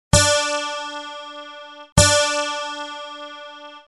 Pst... Klicka på tonerna så kan du stämma din gitarr efter ljudet!